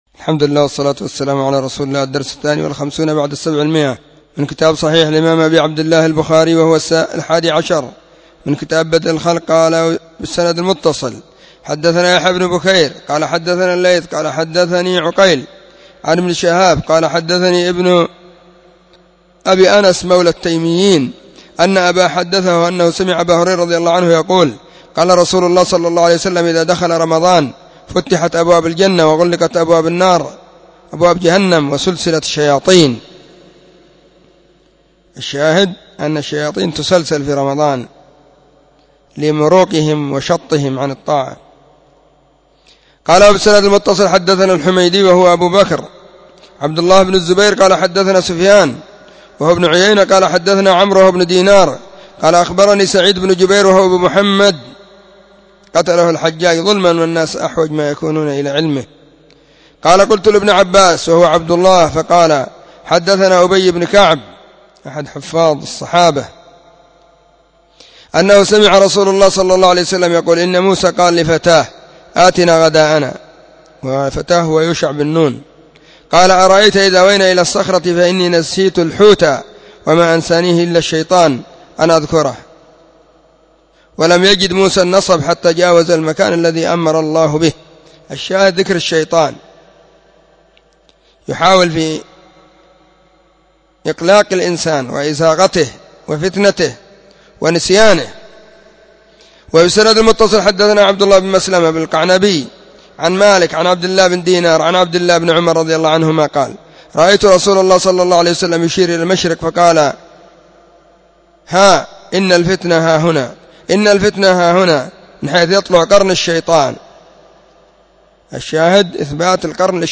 🕐 [بين مغرب وعشاء – الدرس الثاني] 📢 مسجد الصحابة – بالغيضة – المهرة، اليمن حرسها الله.
🕐 [بين مغرب وعشاء – الدرس الثاني]